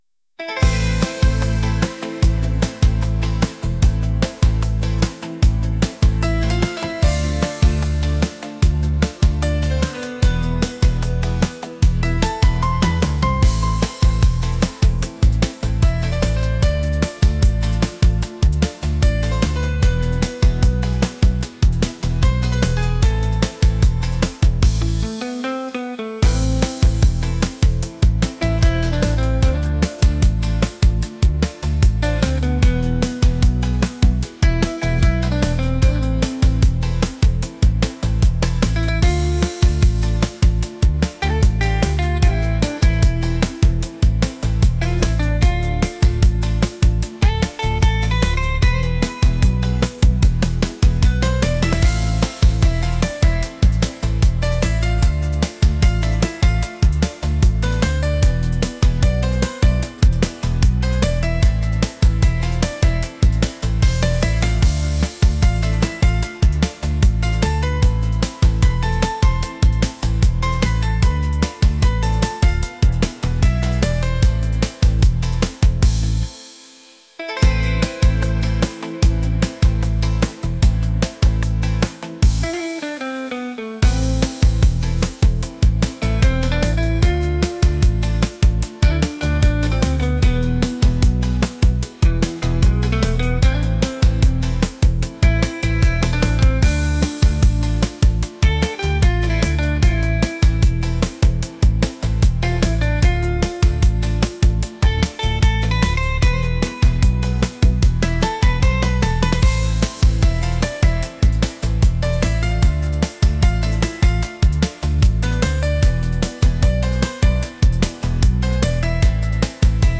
pop | energetic